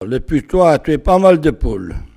Sables-d'Olonne (Les)
locutions vernaculaires